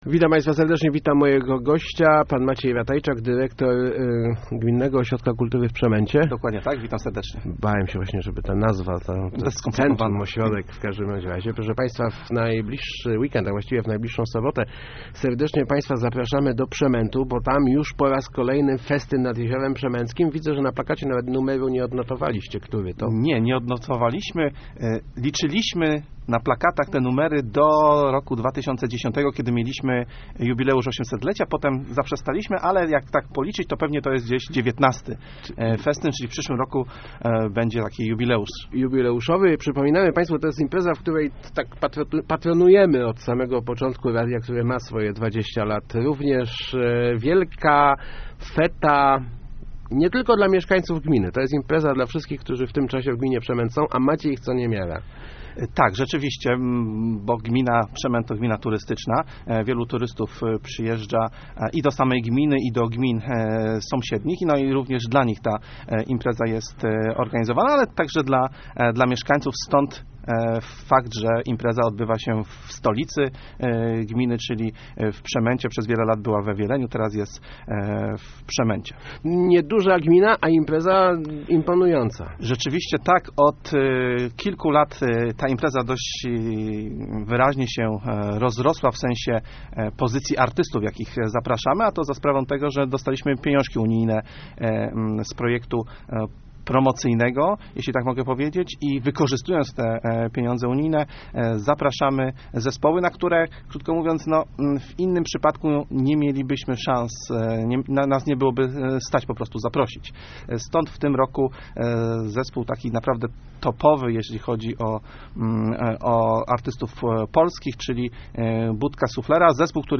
Start arrow Rozmowy Elki arrow Przemęt zaprasza na festyn